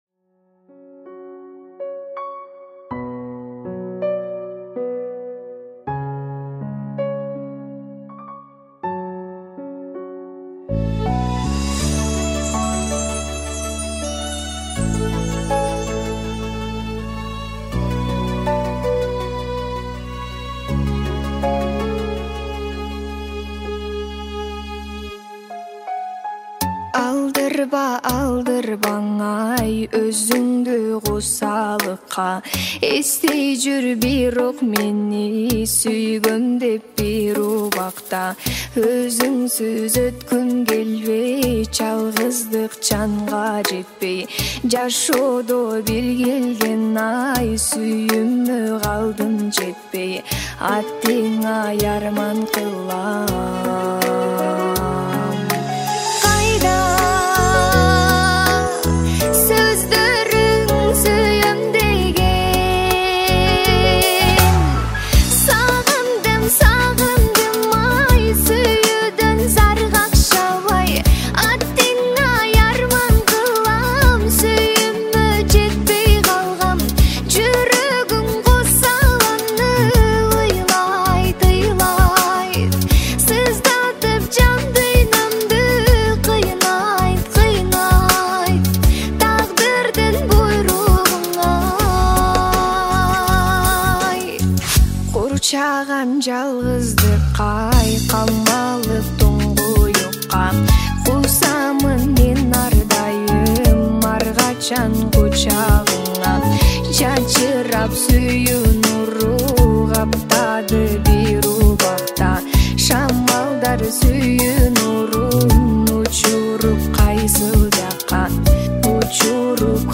Кыргызские песни